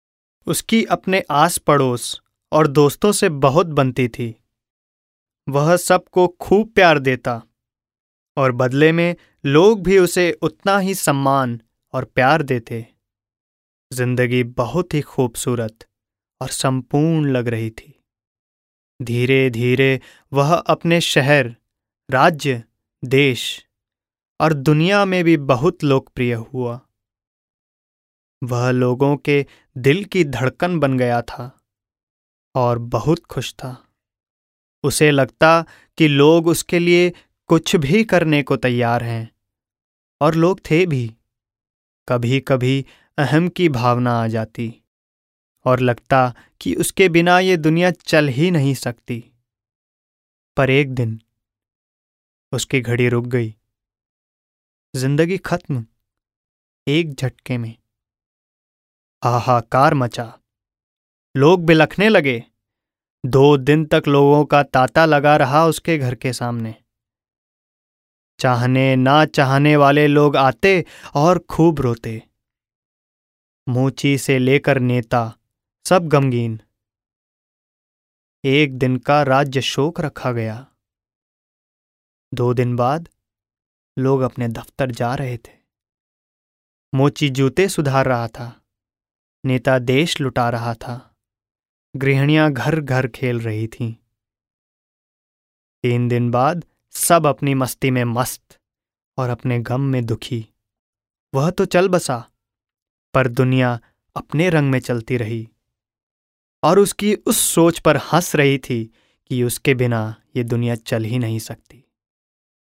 Young, middle aged voice with great command over diction in Hindi, English (Indian) and Marwari (Rajasthani).
Sprechprobe: Sonstiges (Muttersprache):